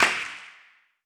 CDK Loud Clap 2.wav